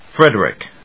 音節Fred・er・ick 発音記号・読み方
/frédrɪk(米国英語), ˈfredrɪk(英国英語)/